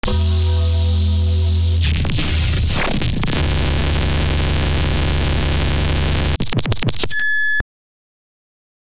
OTT Artifact 4.wav